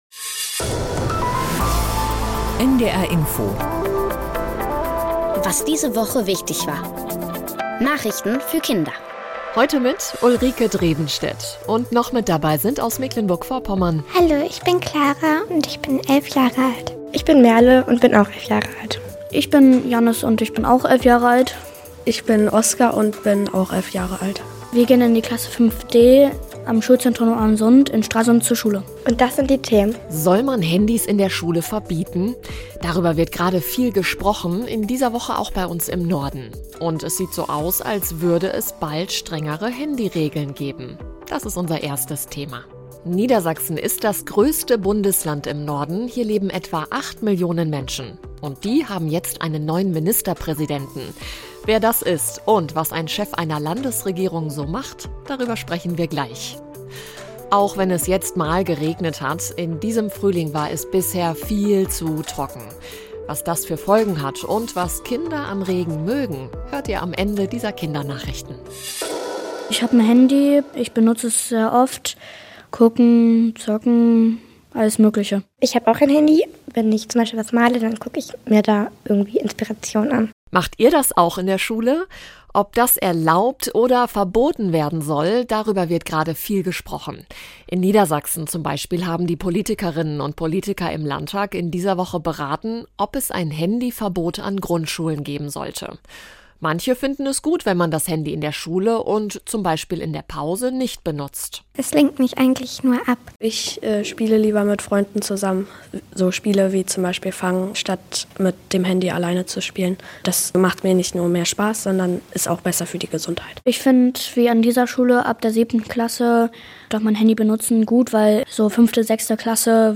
Was diese Woche in Deutschland und der Welt wichtig war erfährst du jeden Samstag hier in den Nachrichten für Kinder von NDR Info. Wir sprechen mit Kindern über die Nachrichtenthemen der Woche und erklären sie verständlich in einfacher Sprache.